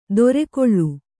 ♪ dorekoḷḷu